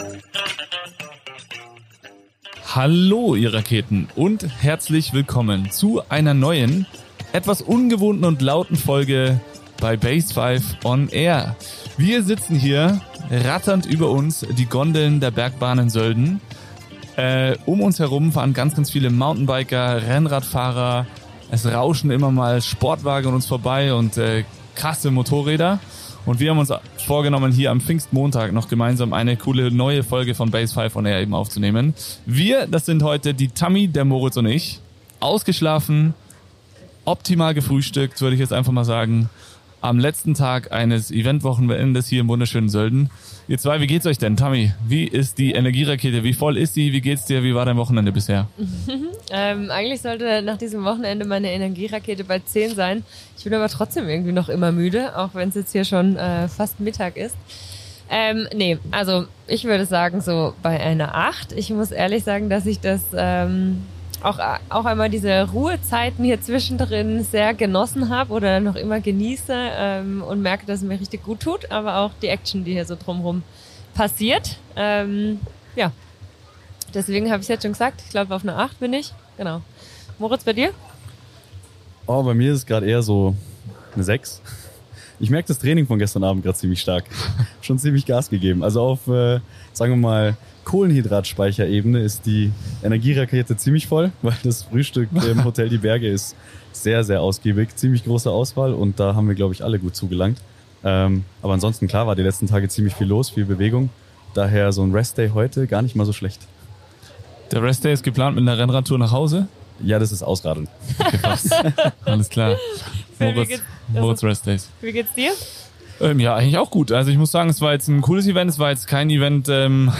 live und leicht zerzaust direkt aus dem wunderschönen Sölden – zwischen Gondellärm, Mountainbikes und einigen kulinarischen Highlights. Sie sprechen über das allererste BASEFIVE Summer Kickstart Event in Kooperation mit dem Tourismusverband Sölden.